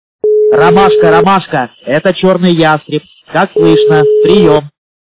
» Звуки » Смешные » Мужской голос - Ромашка, ромашка, это Черный ястреб, как слышно? Прием
При прослушивании Мужской голос - Ромашка, ромашка, это Черный ястреб, как слышно? Прием качество понижено и присутствуют гудки.
Звук Мужской голос - Ромашка, ромашка, это Черный ястреб, как слышно? Прием